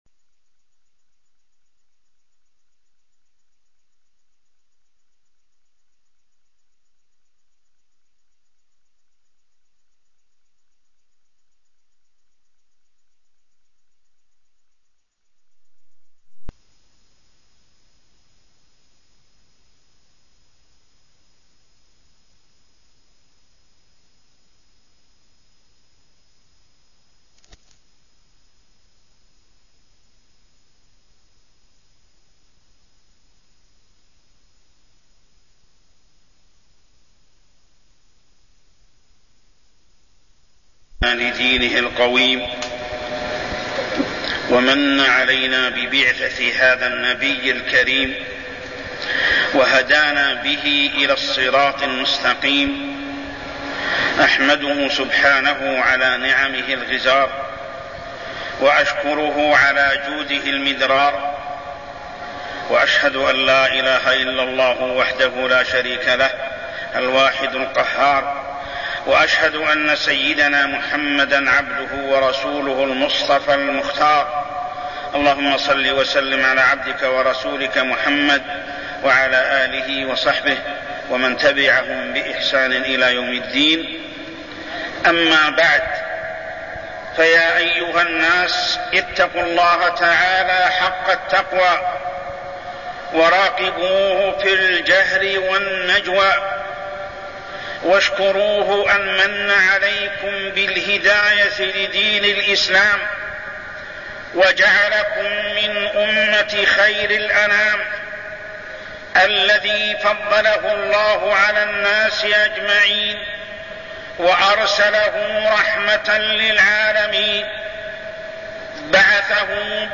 تاريخ النشر ١٢ ربيع الأول ١٤١٥ هـ المكان: المسجد الحرام الشيخ: محمد بن عبد الله السبيل محمد بن عبد الله السبيل القرآن كتاب هداية